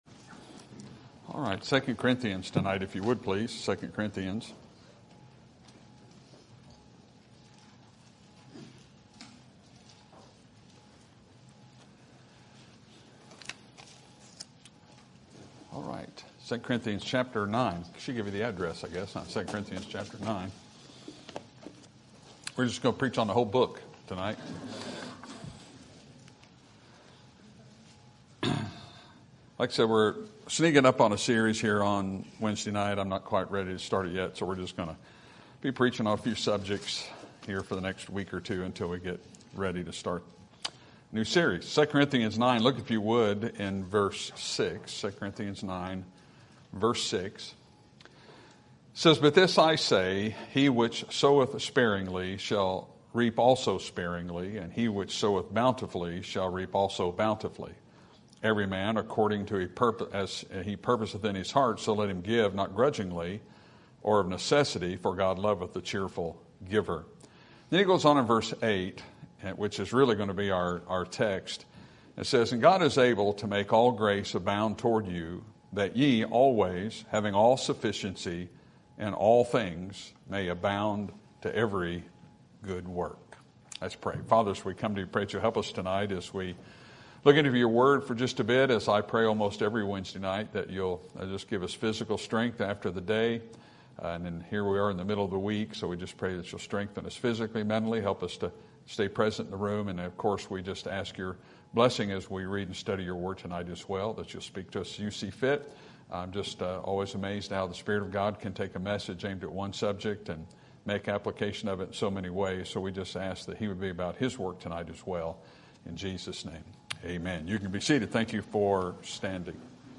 Sermon Topic: Doctrinal Truths Sermon Type: Series Sermon Audio: Sermon download: Download (25.02 MB) Sermon Tags: 2 Corinthians Doctrinal Spiritual Prosperity